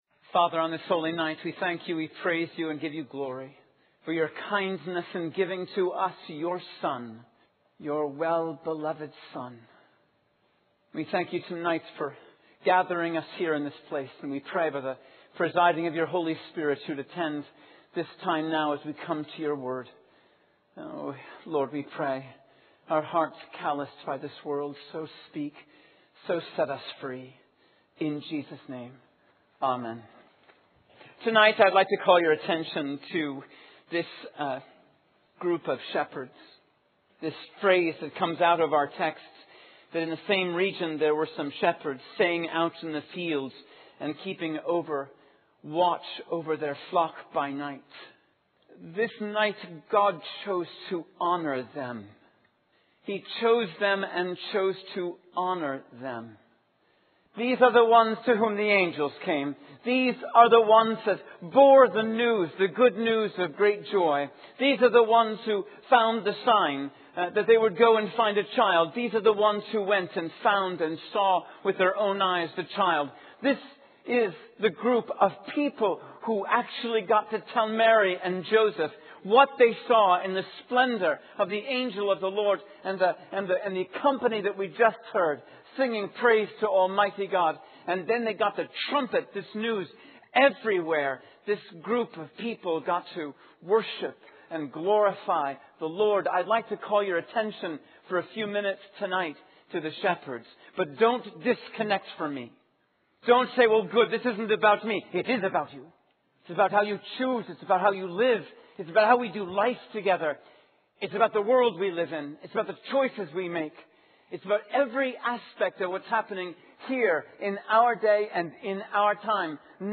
In this sermon, the speaker emphasizes the importance of being in service to others rather than solely focusing on oneself. He uses the analogy of a shepherd and his flock to illustrate this point. The speaker also discusses the need for leaders and shepherds who can navigate the increasing darkness in the world.